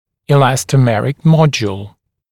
[ɪˌlæstə’merɪc ‘mɔdjuːl] [‘mɔʤ-][иˌлэстэ’мэрик ‘модйу:л] [‘модж-]резиновый модуль